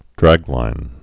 (drăglīn)